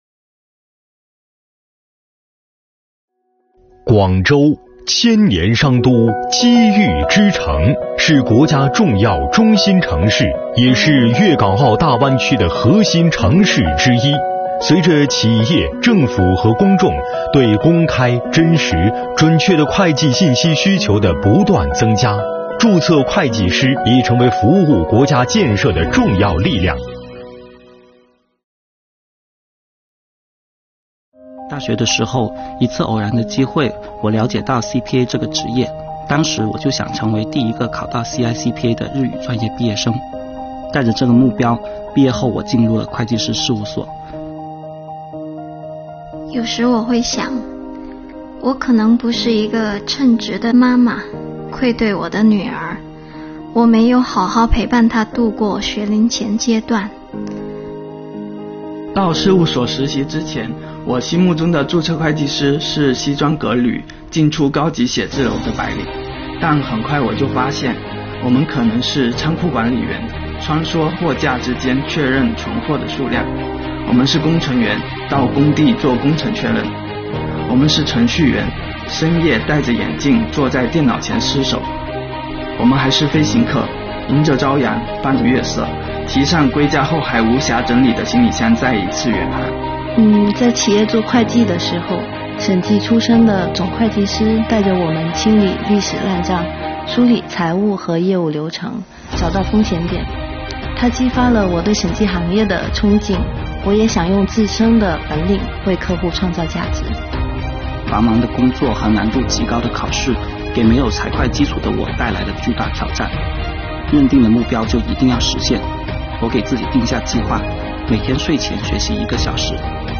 本片以注册会计师的职业发展为主线，由四位青年注册会计师亲诉从初入行业到成为专业、审慎、诚信的注册会计师的成长之路。